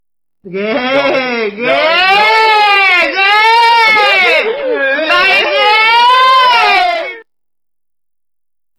Thể loại: Câu nói Viral Việt Nam
cau-noi-viral-ghe-ghe-ghe-pewpew-www_tiengdong_com.mp3